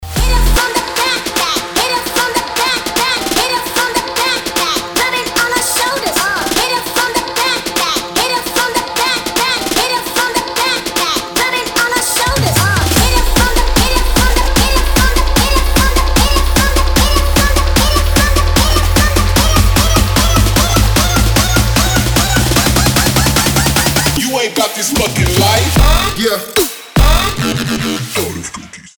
• Качество: 320, Stereo
мужской голос
громкие
Trap
забавный голос